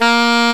Index of /m8-backup/M8/Samples/Fairlight CMI/IIX/REEDS
DELSAX2.WAV